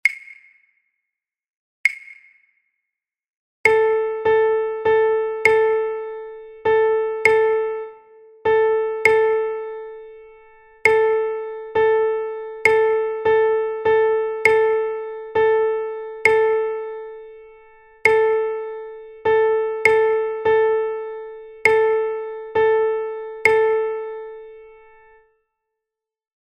Rhythmic dictation. Duplets
dictado_ritmico_2_dosillo.mp3